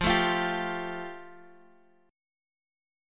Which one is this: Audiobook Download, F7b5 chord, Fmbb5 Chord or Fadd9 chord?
Fadd9 chord